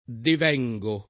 vai all'elenco alfabetico delle voci ingrandisci il carattere 100% rimpicciolisci il carattere stampa invia tramite posta elettronica codividi su Facebook devenire v.; devengo [ dev $jg o ], devieni — coniug. come venire